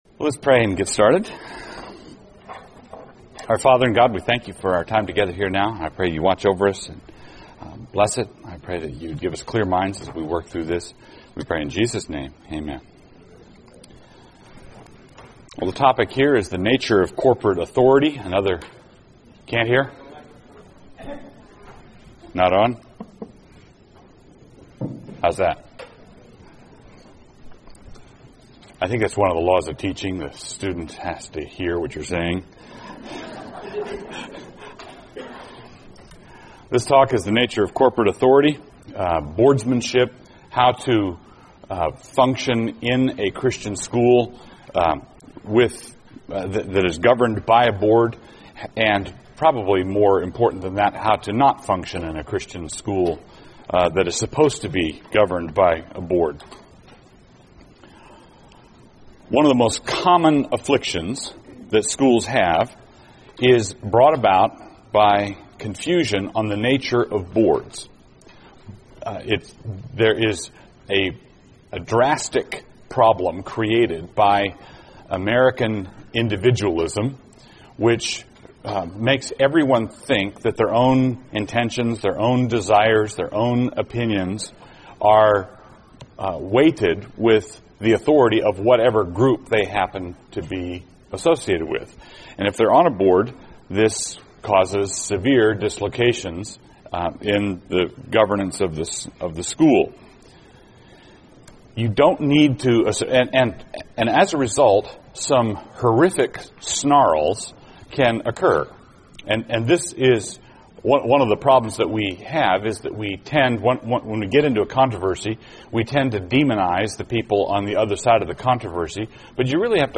2006 Workshop Talk | 0:57:57 | Leadership & Strategic